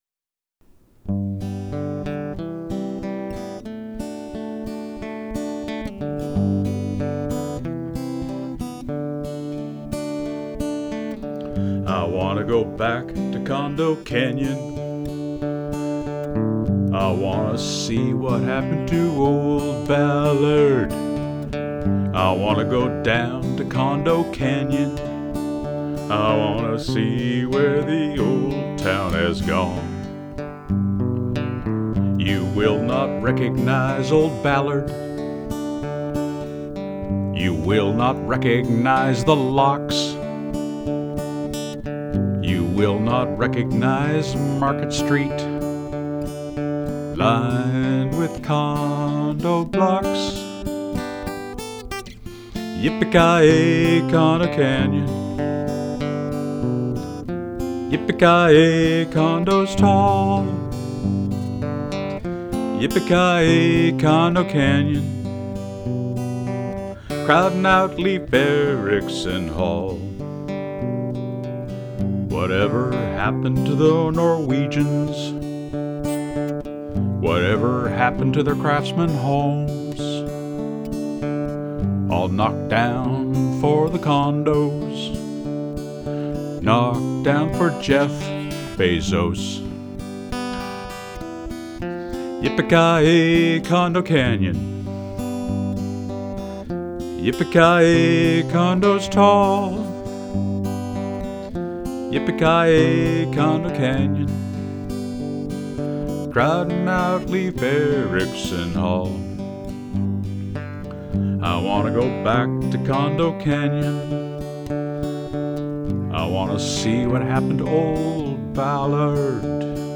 Well there you have it ladies and gentlemen, another fine folk song to mark the age we live in, where things of intrinsic value are swept aside in favor of the “Tyranny of the New”.